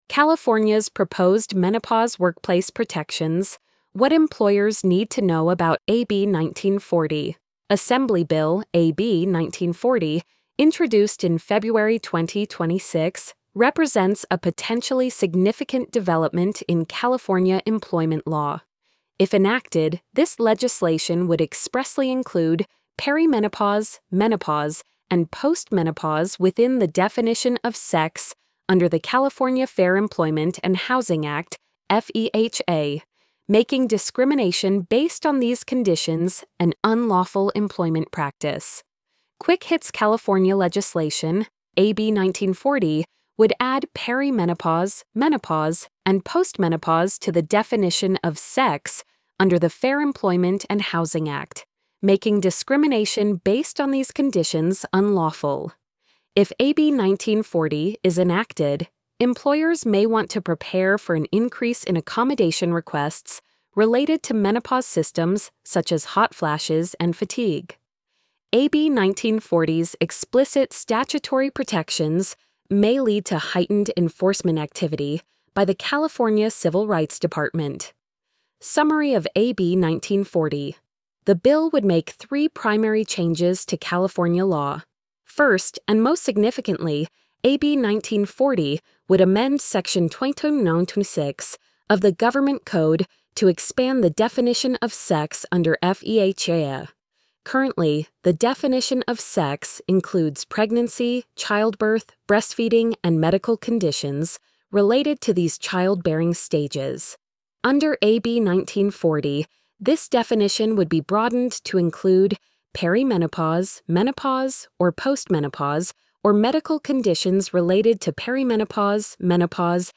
post-86269-tts.mp3